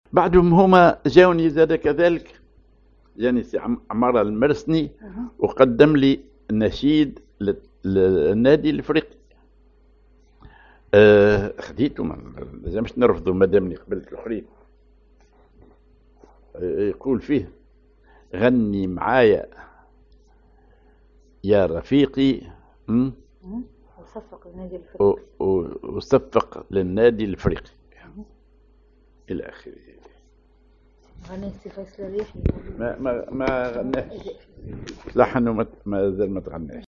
Maqam ar نهوند
genre نشيد